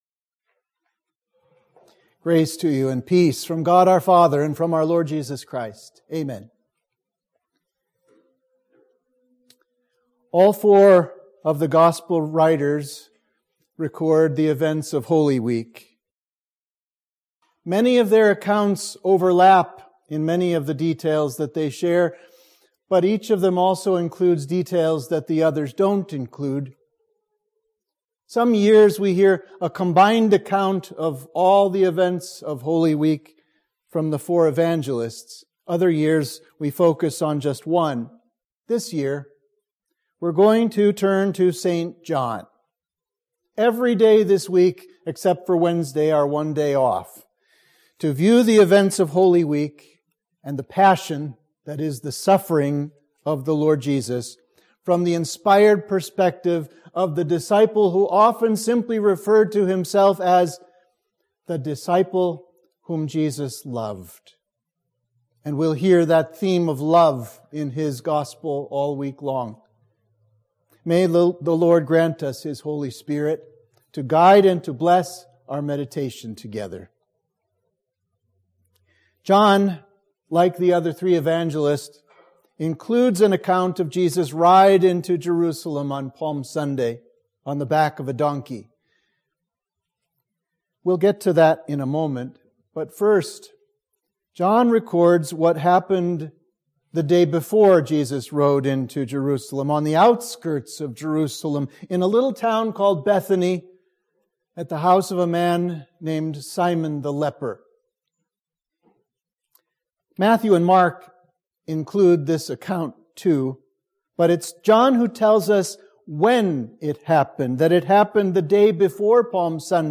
Sermon for Palm Sunday